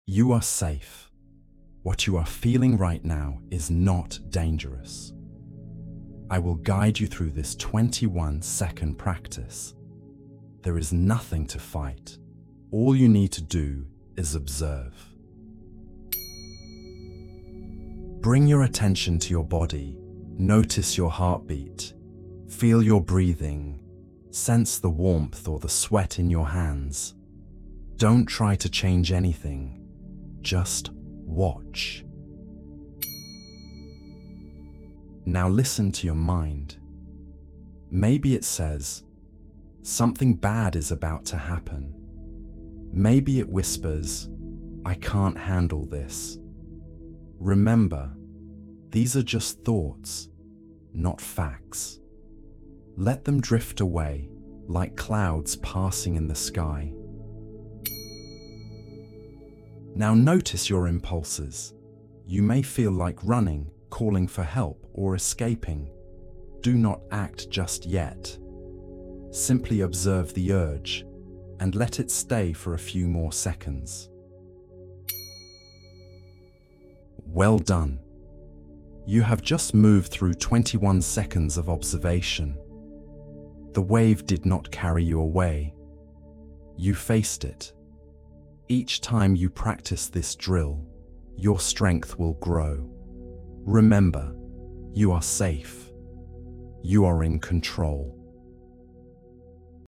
• 8 guided tracks (breathing, instant reset, powerful meditations).
Track-1-21-Second-Awareness-Drill.mp3